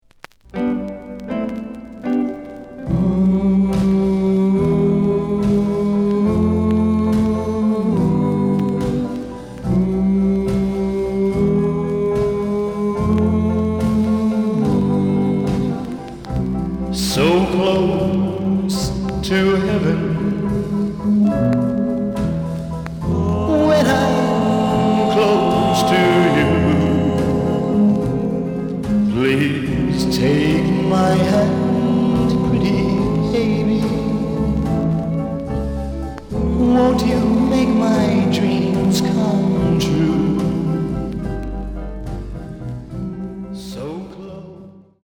The listen sample is recorded from the actual item.
●Format: 7 inch
●Genre: Rhythm And Blues / Rock 'n' Roll